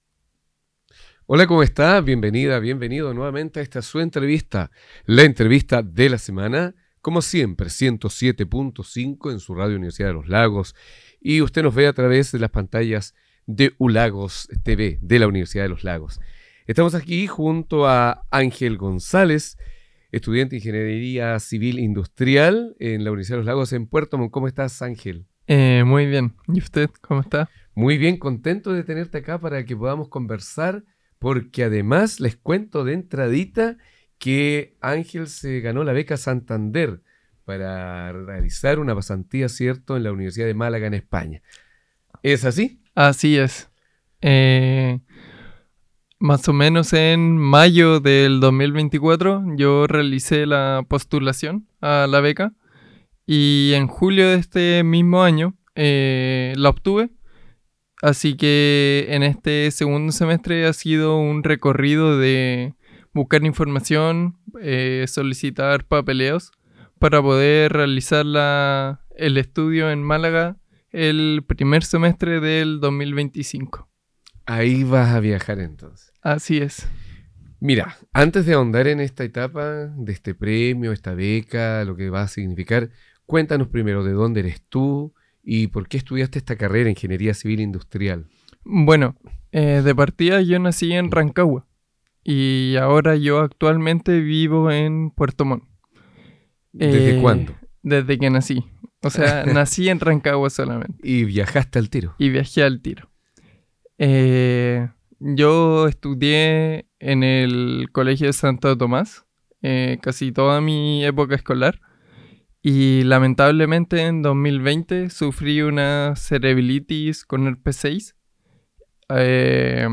Entrevista de la Semana